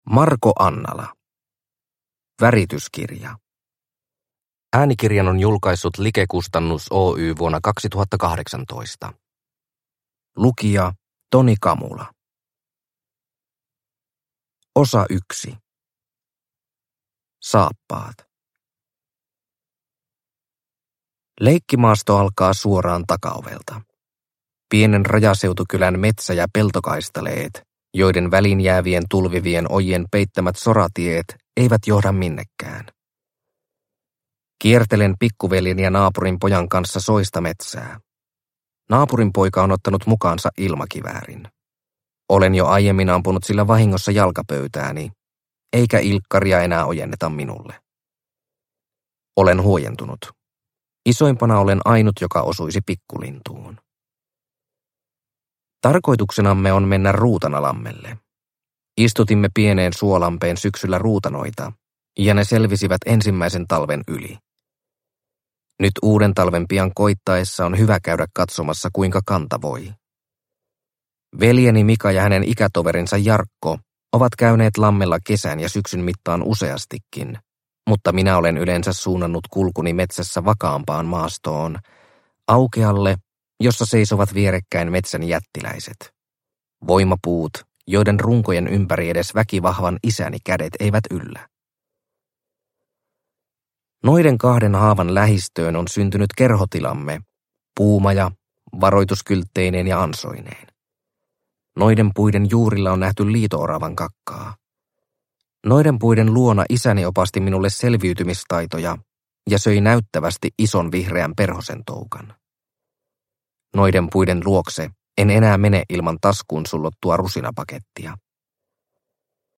Värityskirja – Ljudbok – Laddas ner